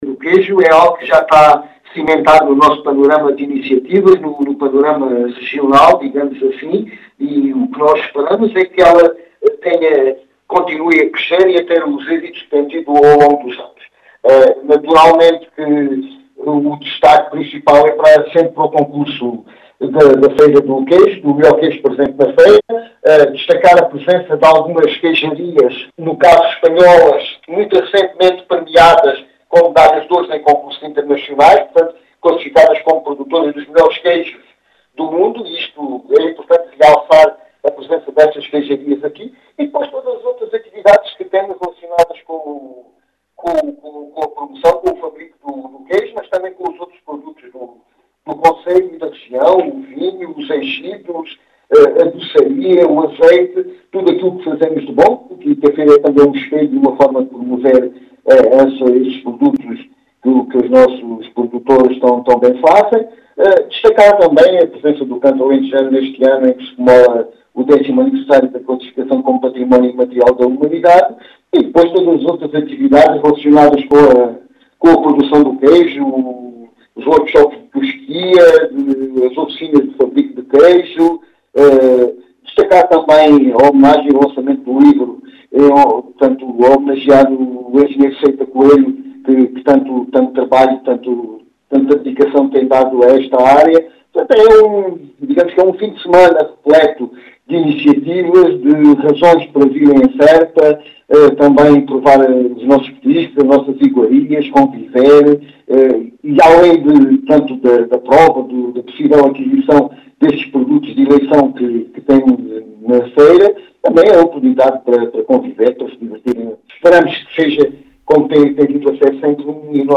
As explicações são de João Efigénio Palma, presidente da Câmara Municipal de Serpa, que deixou os destaques do certame, e boas expectativas para o mesmo.